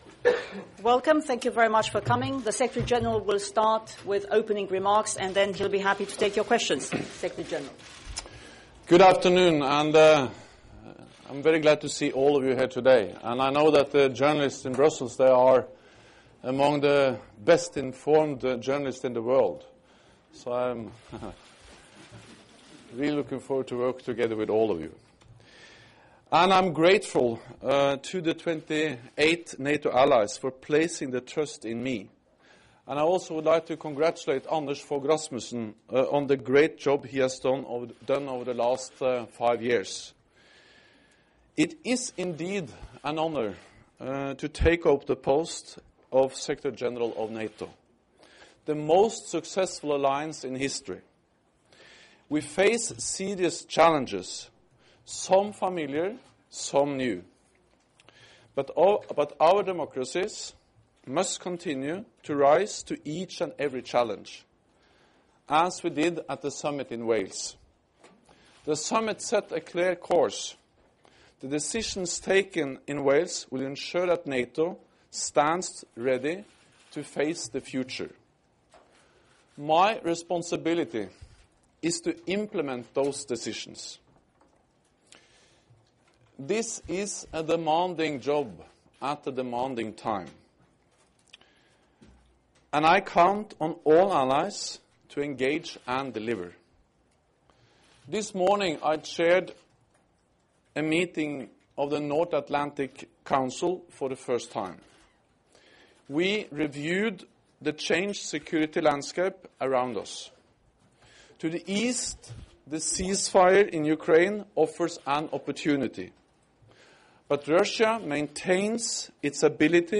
Press conference by incoming NATO Secretary General Jens Stoltenberg